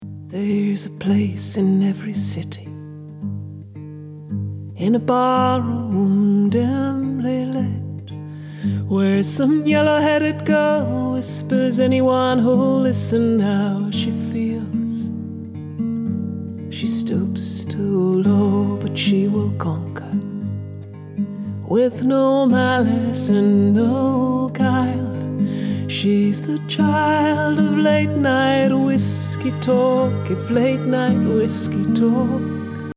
ci dessous vous pouvez écouter le résultat sans correction de volume (c'est vrai que vu le style ça devient un peu ridicule).... enfin pour de la démo, je pense qu'on saisi bien l'effet obtenu !!